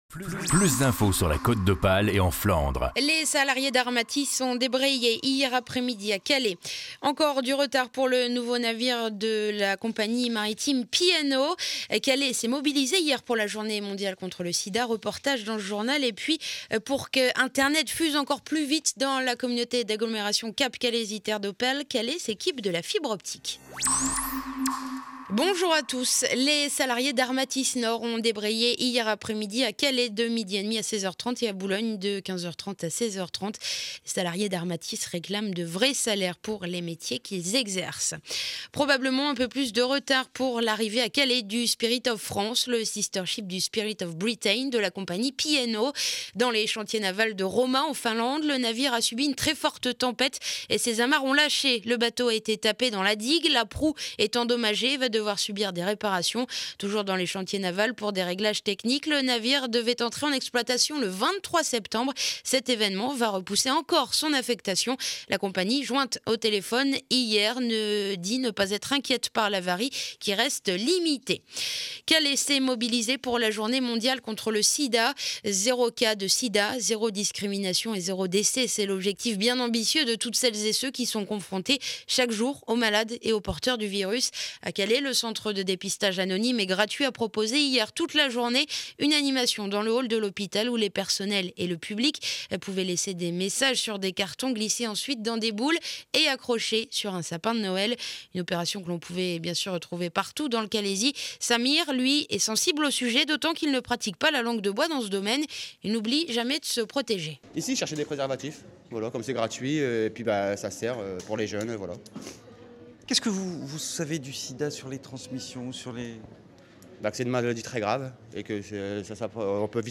journal du vendredi 2 décembre édition de Calais 12h